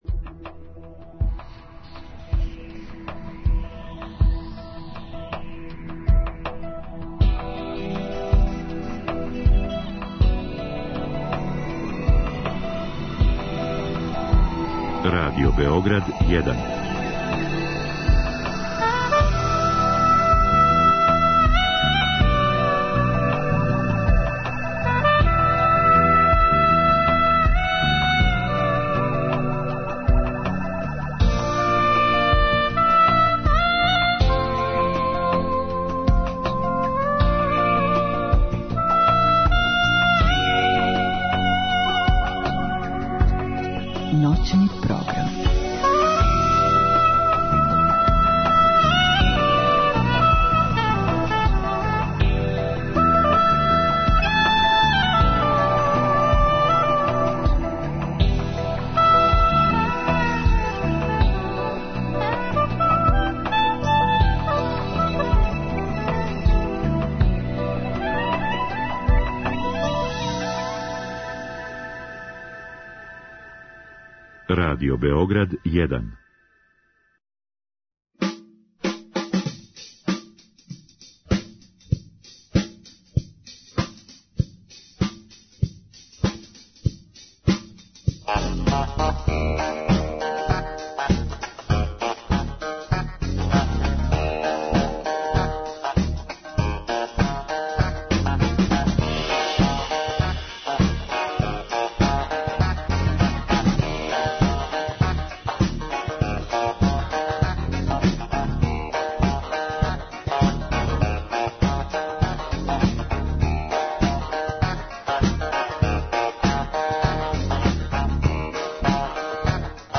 Слушаћемо Новогодишњу и још пуно песама.
Због великог интересовања емисија ће уживо трајати три сата, назваћемо је новогодишња, слушаћемо новогодишњу песму и причати са сјајним и паметним људима.